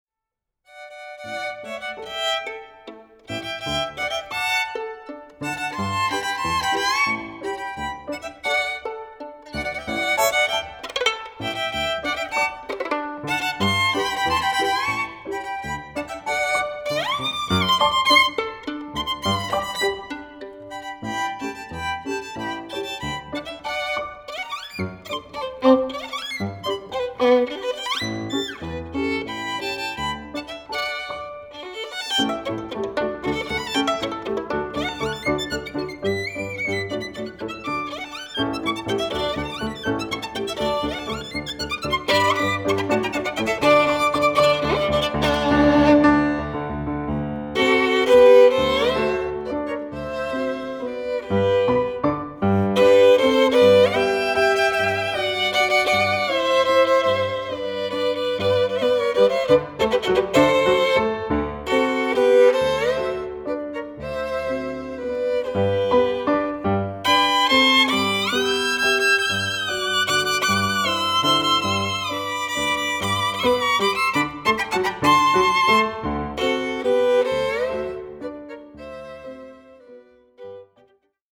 smuikas
fortepijonas